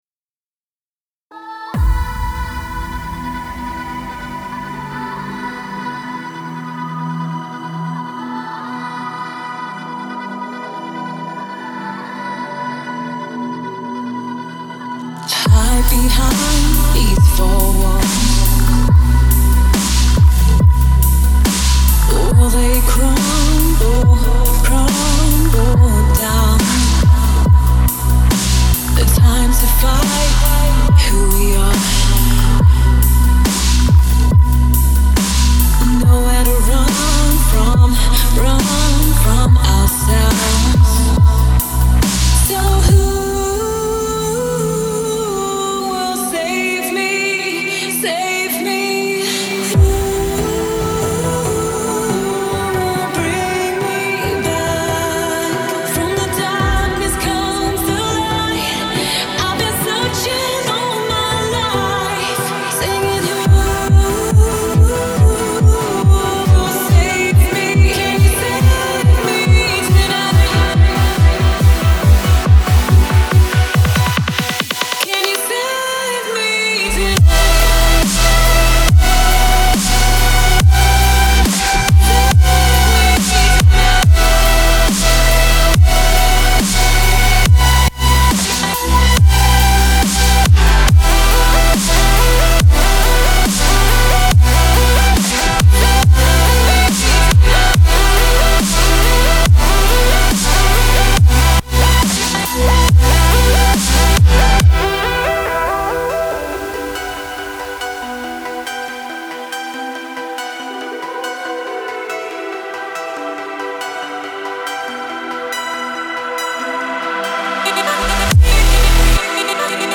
мощная транс-композиция